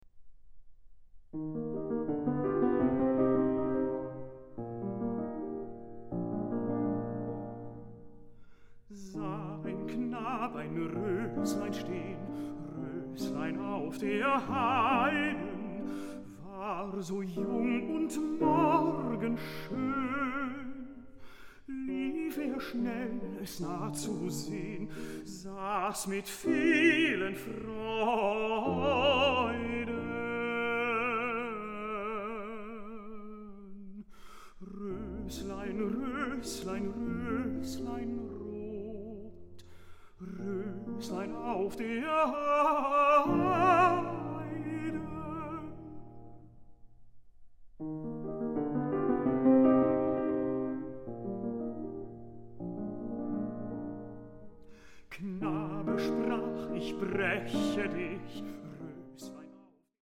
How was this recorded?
Recording: Alfried-Krupp-Saal, Philharmonie Essen, 2024